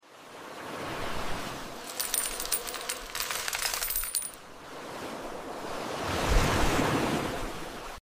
Satisfying ASMR videos of Dollar's